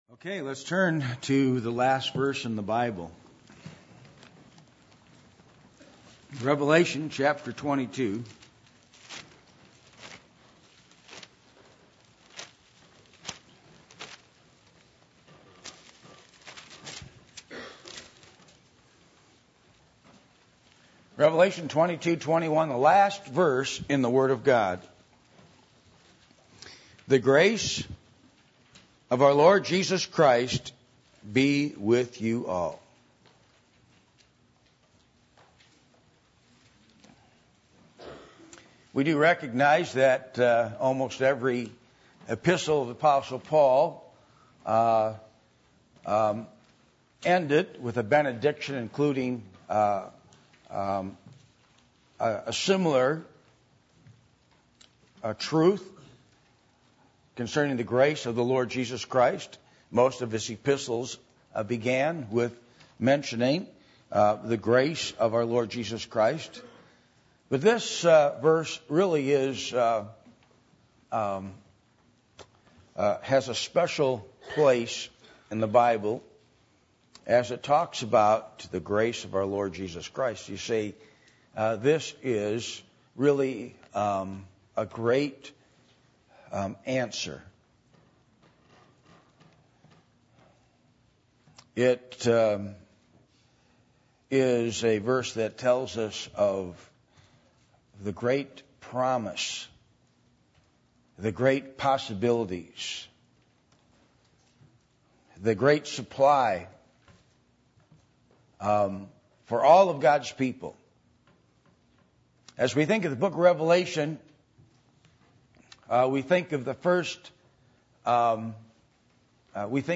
Passage: Revelation 22:21 Service Type: Sunday Morning %todo_render% « Communion With The Triune God